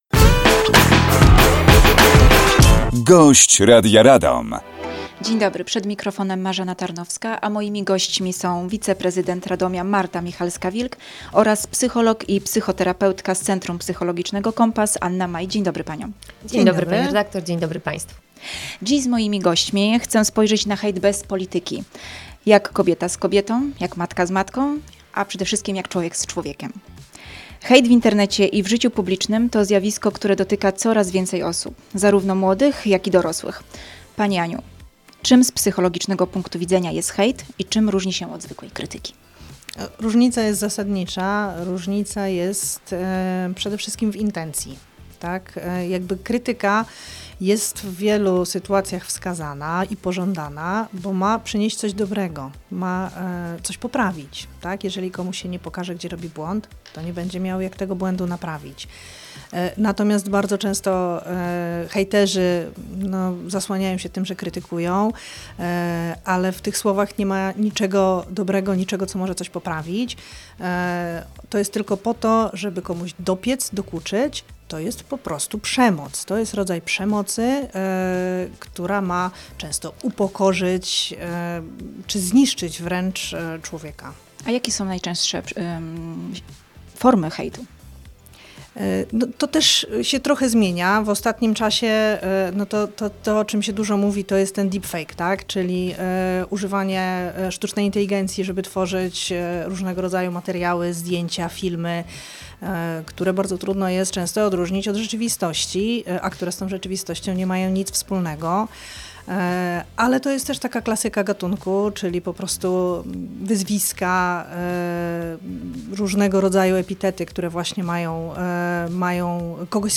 Marta Michalska-Wilk, wiceprezydent Radomia